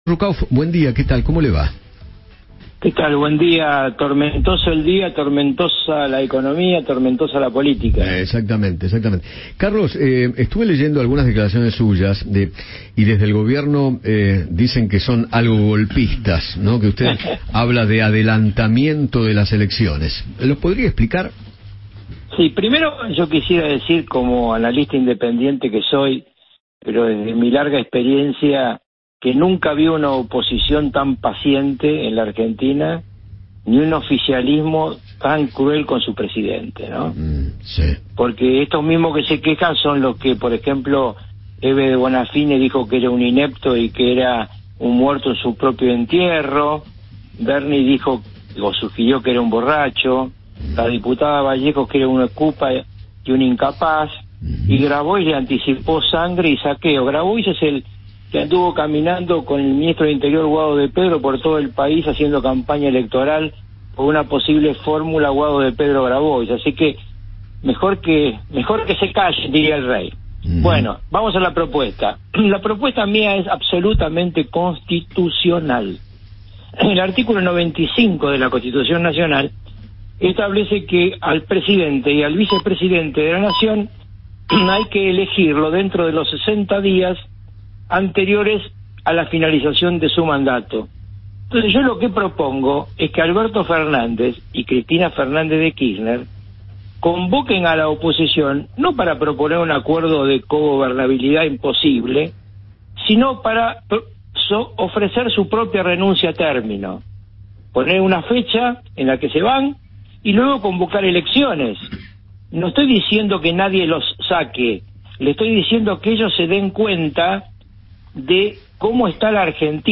Carlos Ruckauf, ex vicepresidente de la Nación, habló con Eduardo Feinmann sobre la actualidad política de la Argentina.